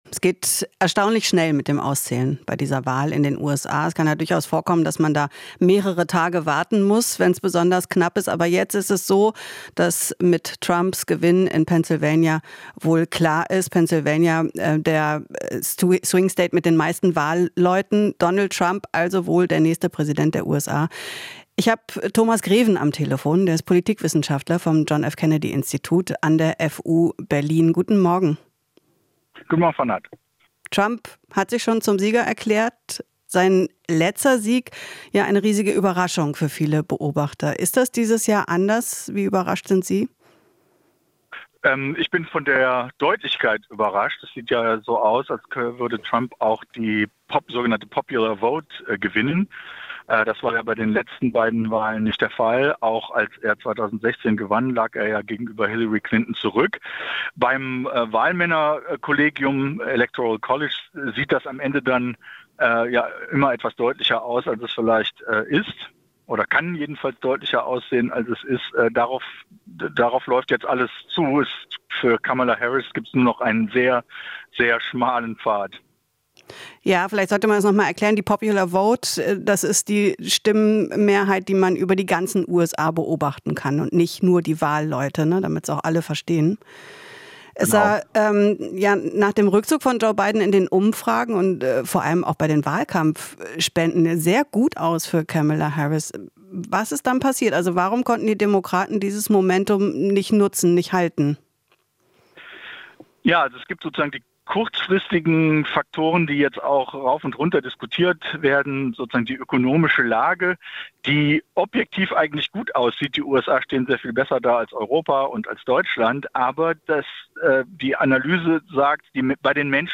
Interview - Politologe: Trump hat Republikaner in Personenkult verwandelt